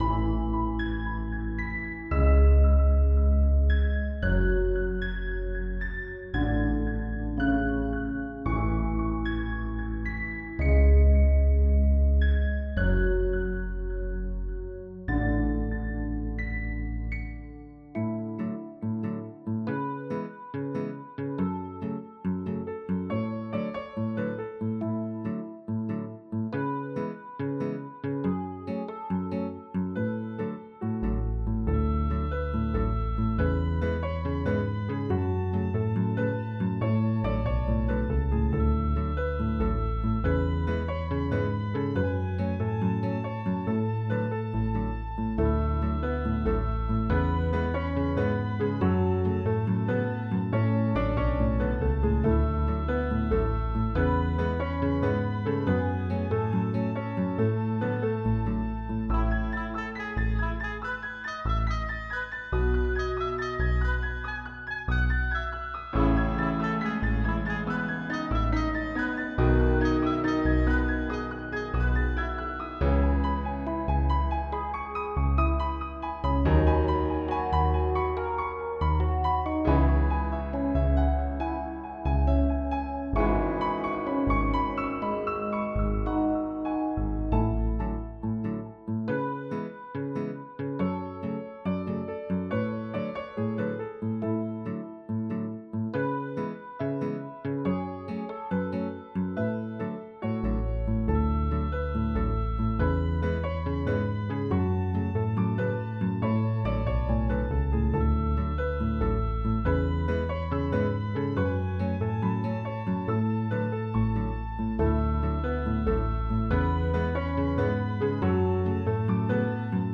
vioolen_zijn_kut_intro.ogg